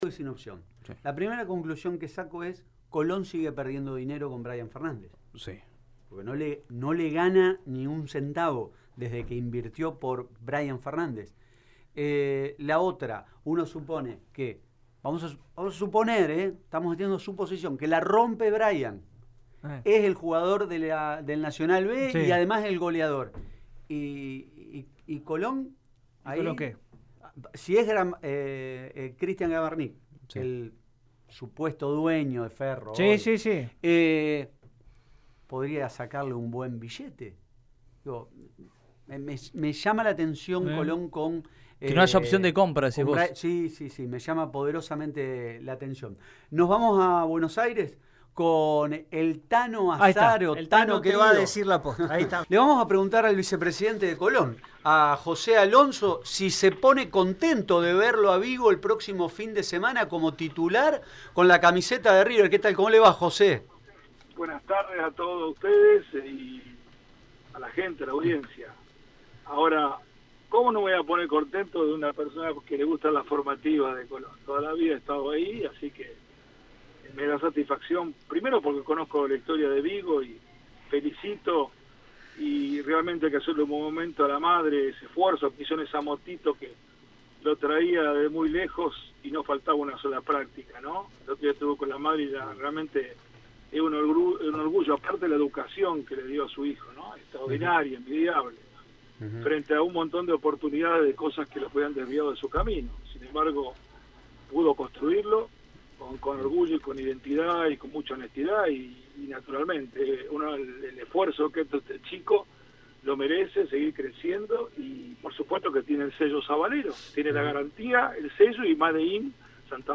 2021 En dialogo con Radio EME Deportivo